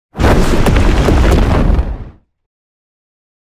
scorching-ray-001-15ft.ogg